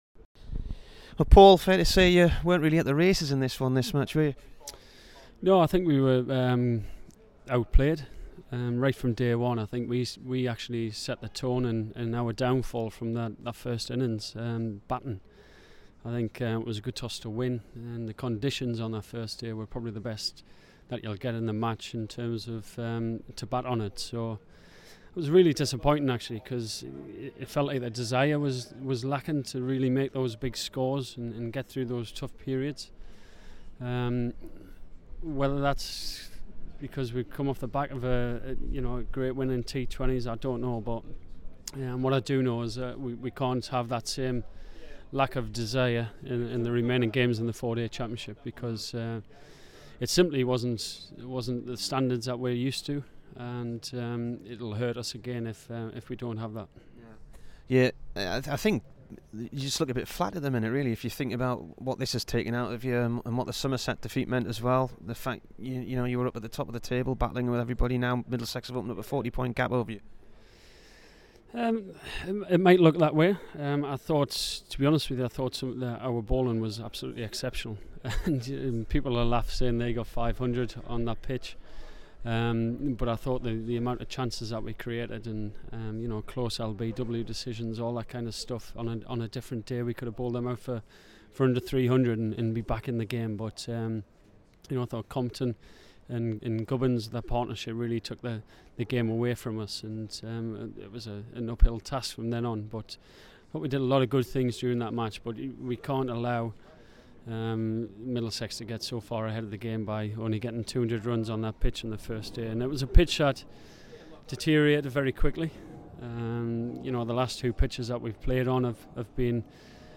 Paul Collingwood int